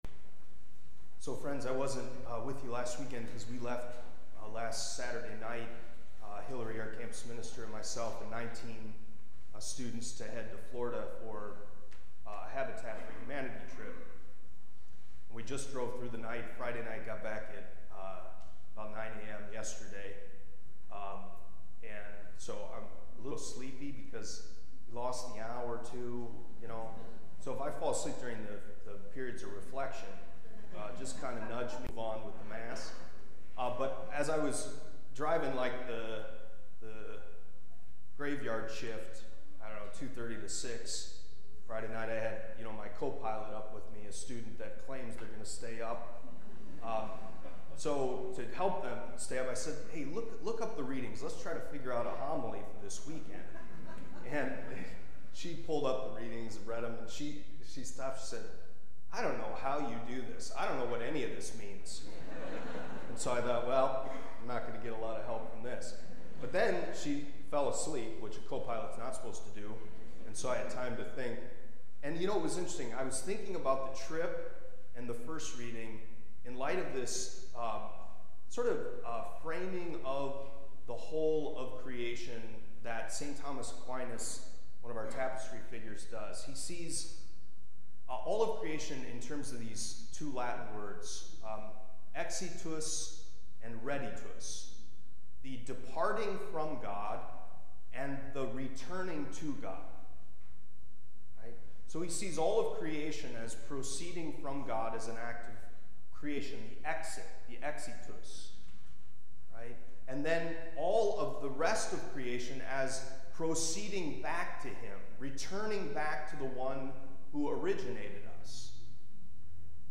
Homily from the Fourth Sunday in Lent, Sunday, March 10, 2024